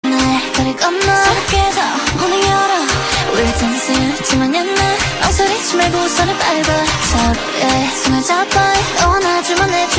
UK garage & vibes